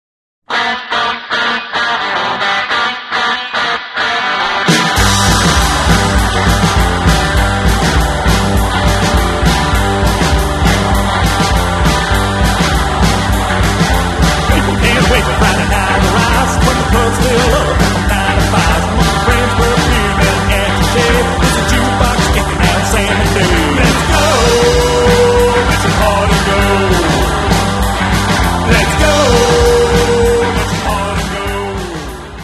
drums
on electric guitar
on bass guitar and me
vocals and occasional saxophone